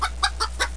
hen.mp3